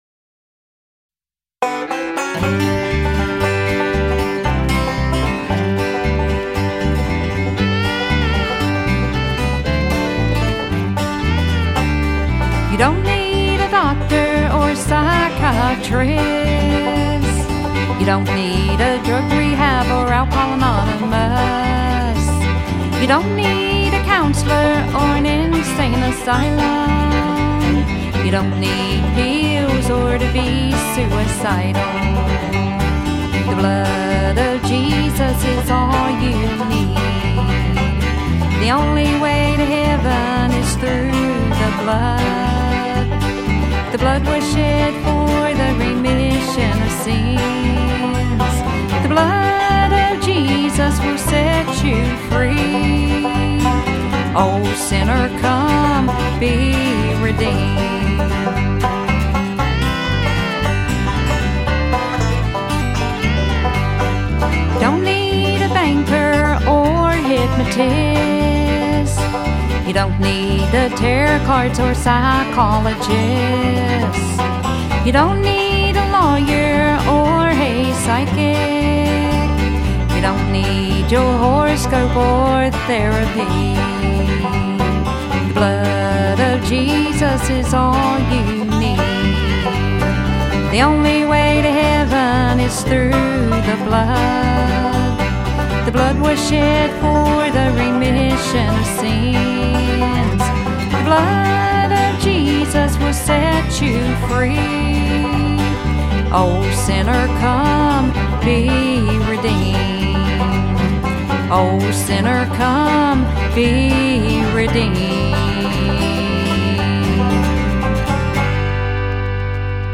southern country and bluegrass songs